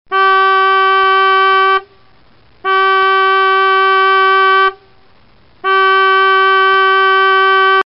Здесь вы найдете разнообразные аудиозаписи: от мягкого гула двигателя на малых оборотах до мощного рева моторов на полном ходу.
Гудок речной лодки звук сигнала